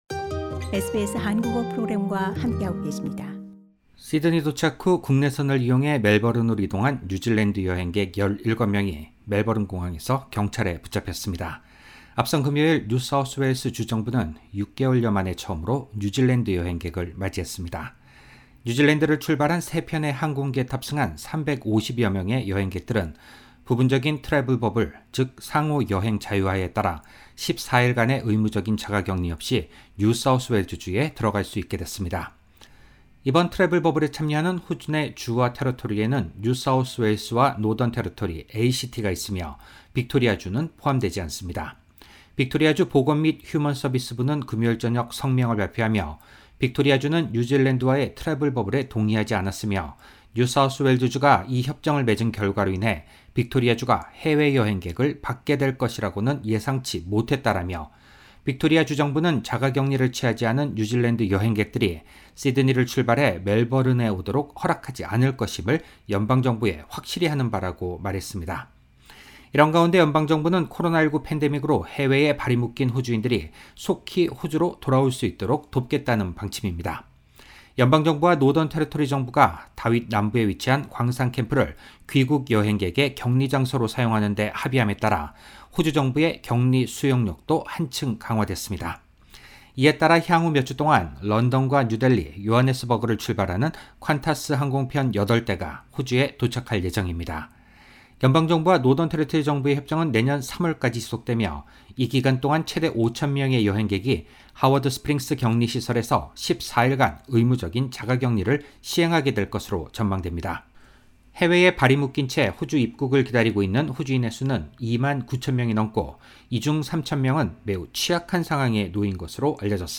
korean_17_10_audio_news.mp3